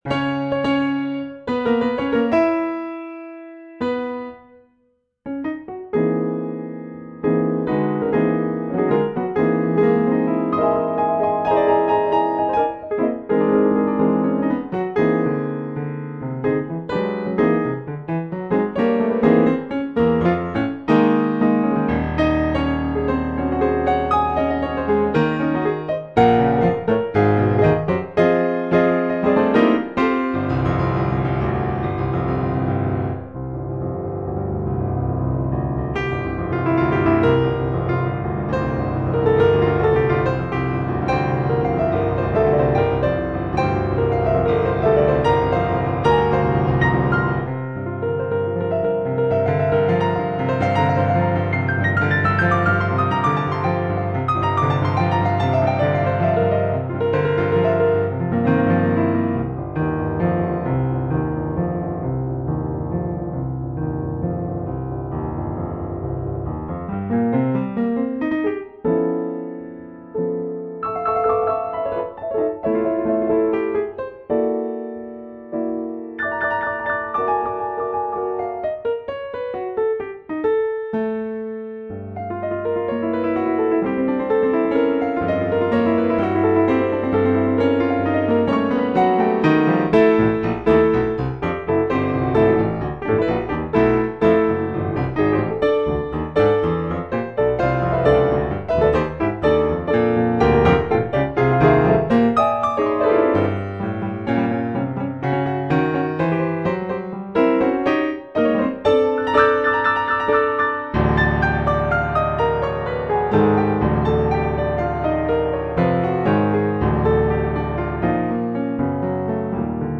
Here are recordings (mp3 files) in which I play eight of my piano compositions.
Gloria is the final movement of a set of four 'liturgical' piano pieces composed in 2010, substantially transcribed from a setting of the Latin Mass originally composed in 1981-2. The at times quite abrasive and dissonant character of the music is intended to convey a feeling of triumph and exultation overcoming evil and death.